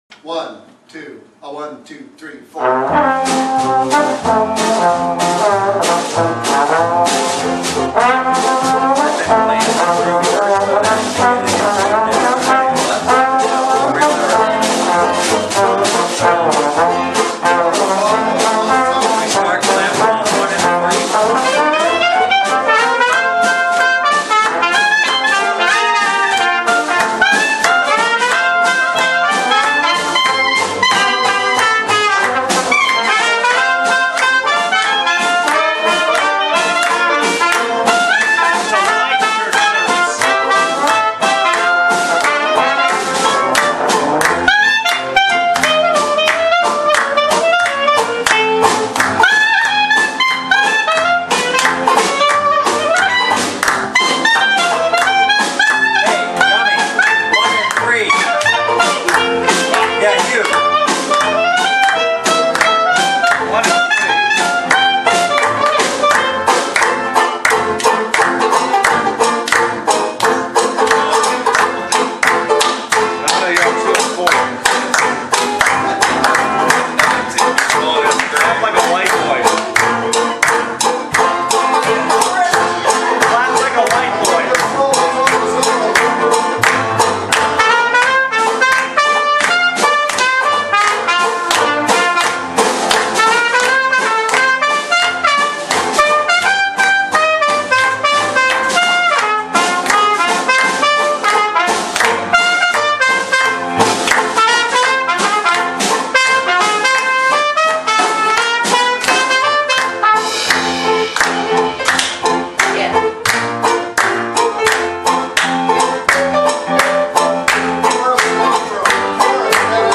ADULT TRADITIONAL JAZZ CAMP 2010
Bass
Banjo
Clarinet
Drums
Trombone
Trumpet
Piano